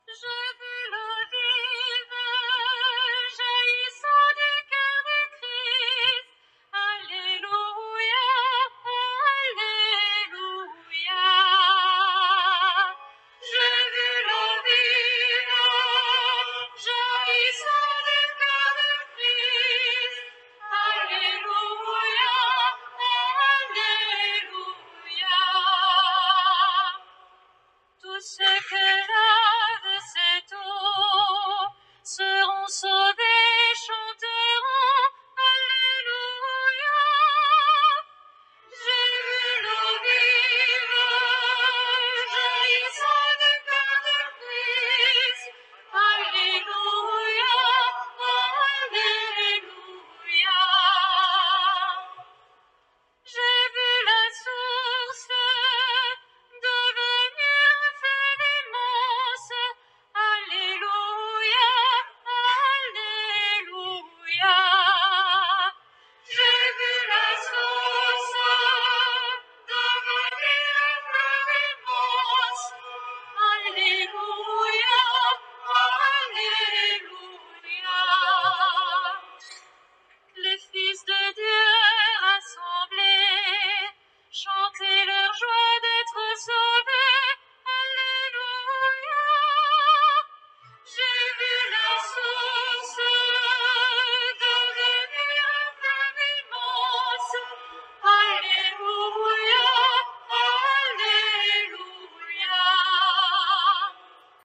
Messe solennelle dans la pro-cathédrale Saint-Etienne de Nevers.
Liturgie baptismale et pénitentielle :